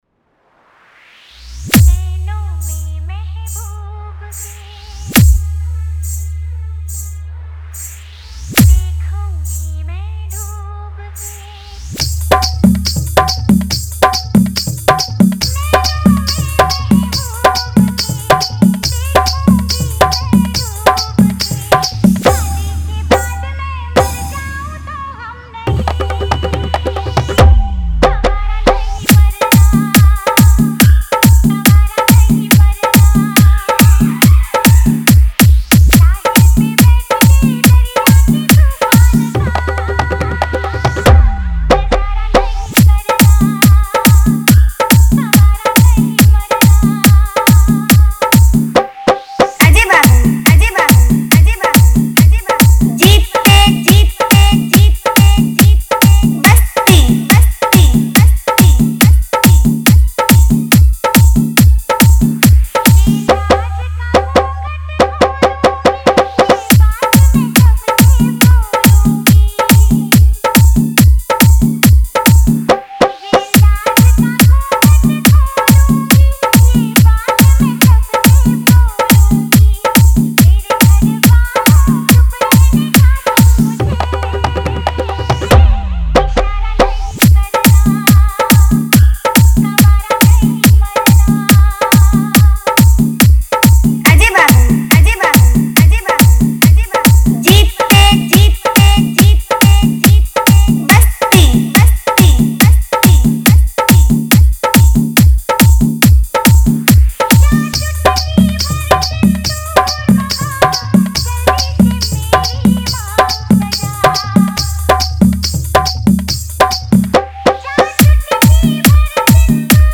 Category : Hindi Wala Dj Remix